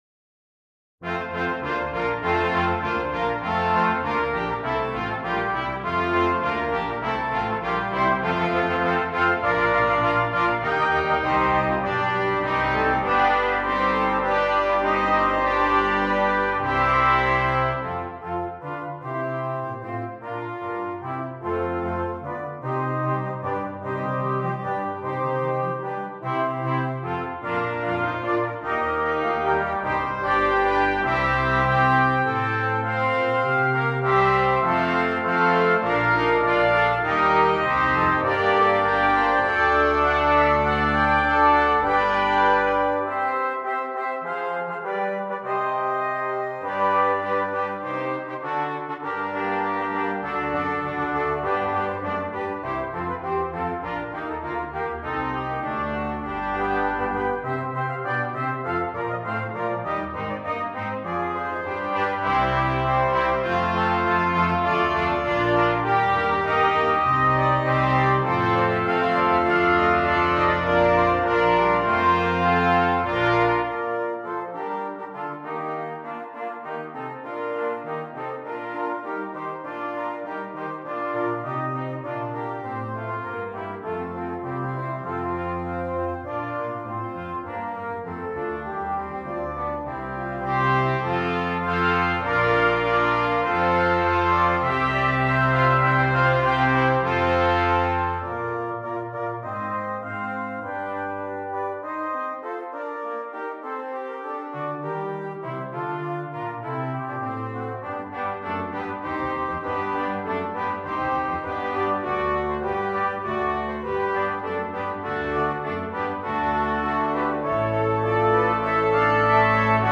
Brass
Double Brass Quintet